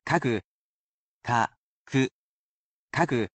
You can repeat after the word pronunciation, but the sentences are at regular speed in order to acclimate those learning to the pace.